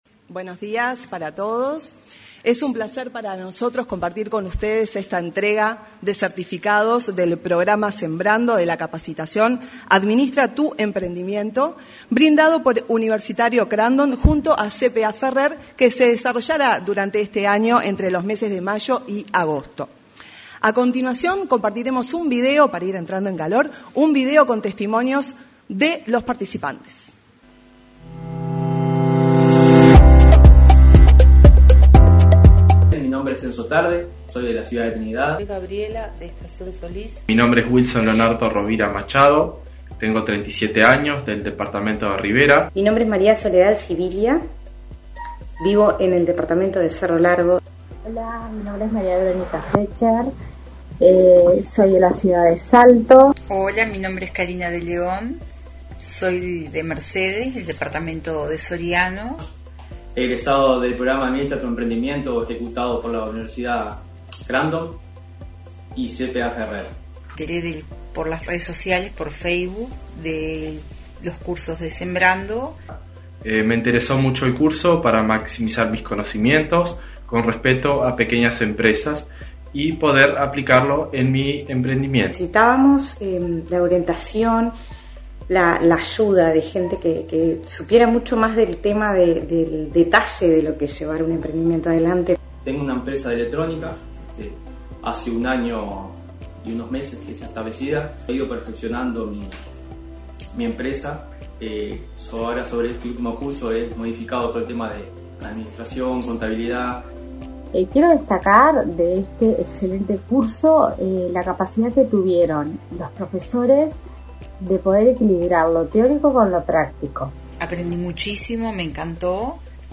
Entrega de diplomas del curso Sembrando Administra tu Emprendimiento 29/09/2022 Compartir Facebook X Copiar enlace WhatsApp LinkedIn Este jueves 29, se realizó en el auditorio del edificio anexo de Torre Ejecutiva la entrega de diplomas del curso Sembrando Administra tu Emprendimiento.